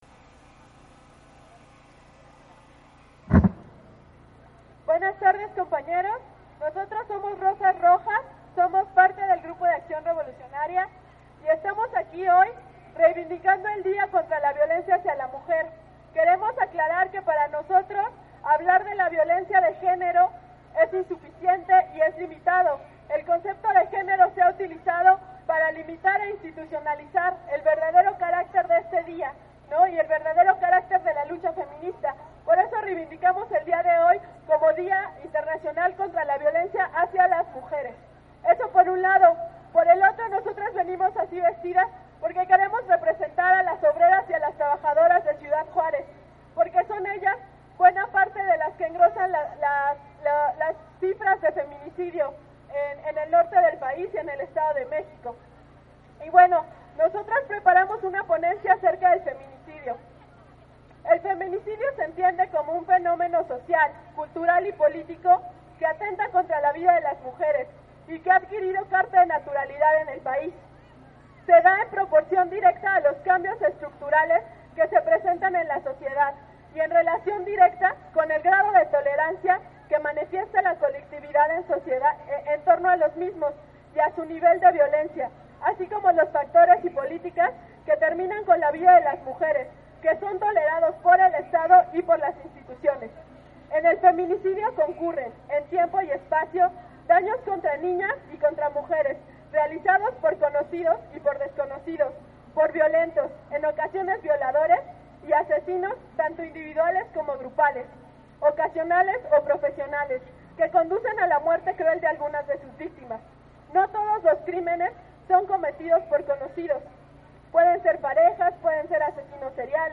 El pasado 25 de noviembre se realizó una marcha por el 25 de noviembre "día internacional contra la violencia a la Mujer"que partió del monumento a la Madre hacia el Palacio de Bellas Artes, la cual inició a medio día, esta fue convocada por colectivos feministas, como Pan y Rosas, Mujeres y la Sexta, entre otras; apróximadamente a las 2 de la tarde arribaron a la av. Juarez donde se llevó acabo la actividad político-cultural.